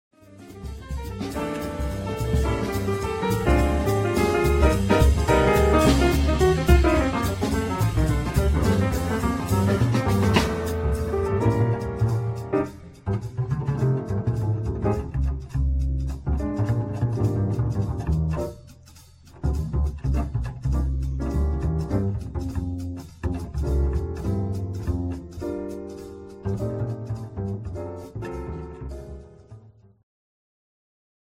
ジャンル Jazz
管楽器フューチュア